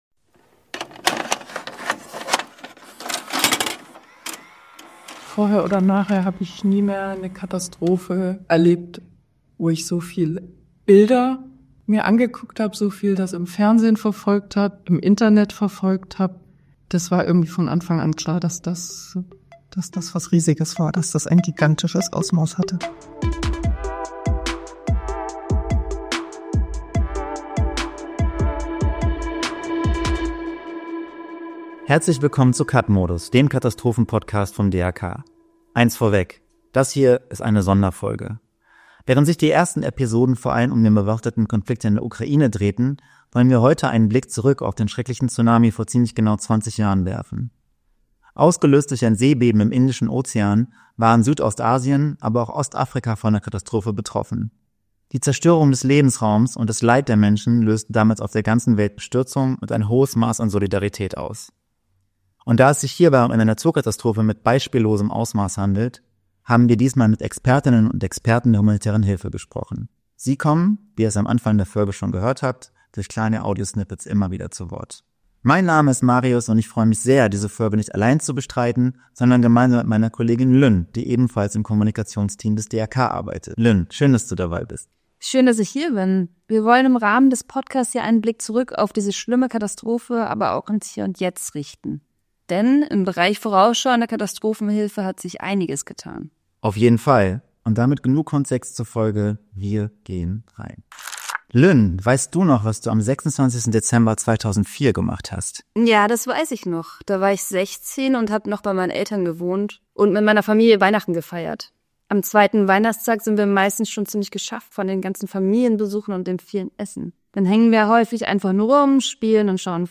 Dafür haben Sie mit Expertinnen und Experten der humanitären Hilfe gesprochen. Auch Sie kommen in dieser Folge immer wieder zu Wort.